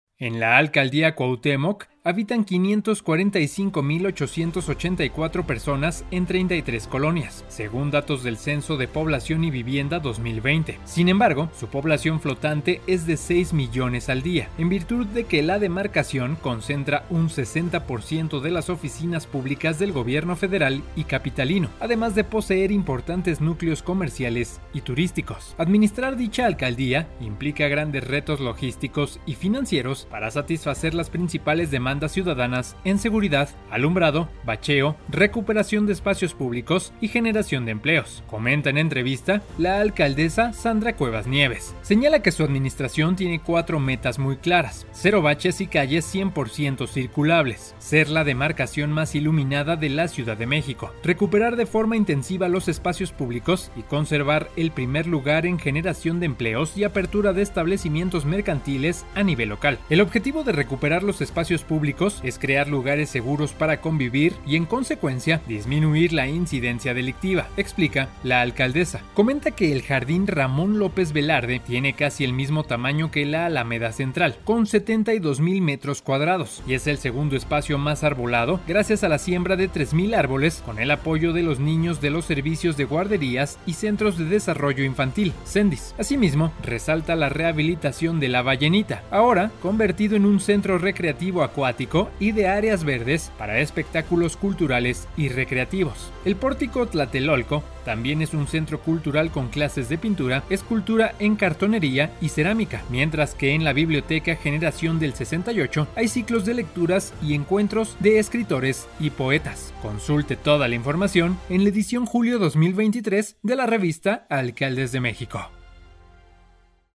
►Espacio Público ♦ Entrevista
Podcast-revista_expediente-abierto-entrevista-Sandra-Cuevas.mp3